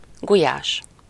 The name originates from the Hungarian gulyás [ˈɡujaːʃ]
Hu-gulyás.ogg.mp3